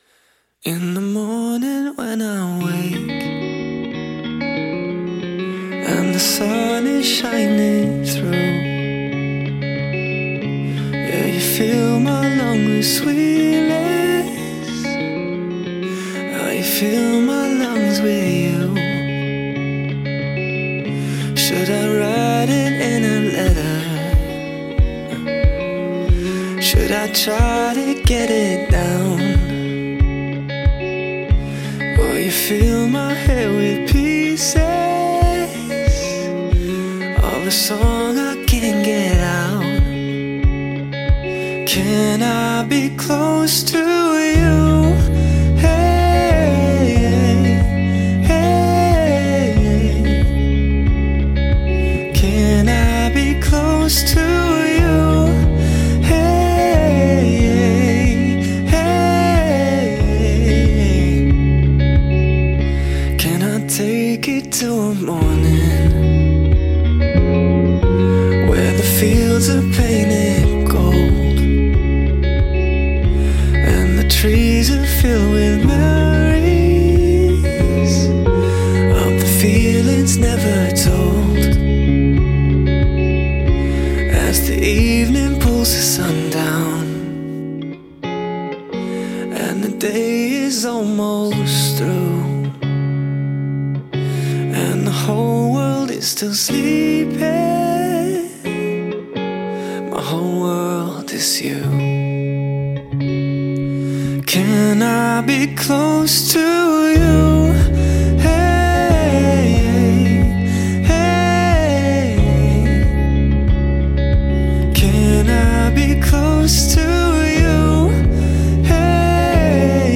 Vocals | Guitar | Looping | DJ | MC